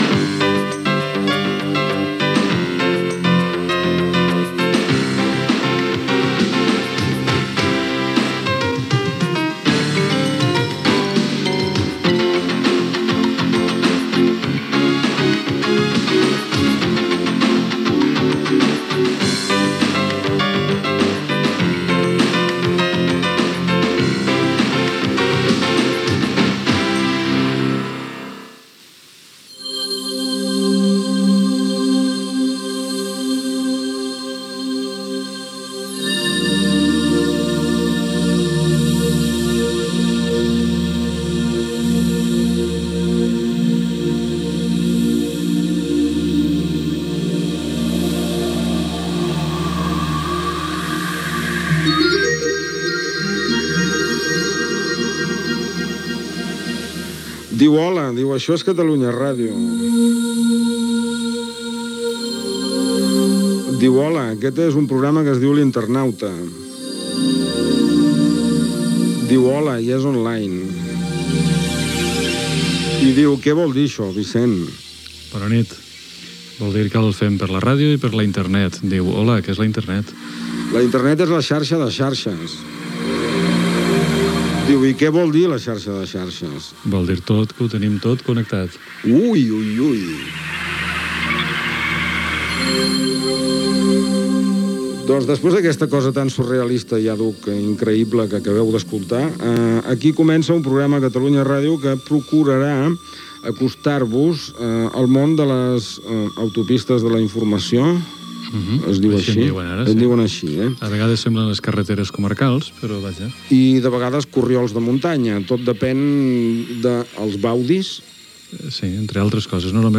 Primer programa de la radiodifusió catalana que es va poder escoltar per Internet.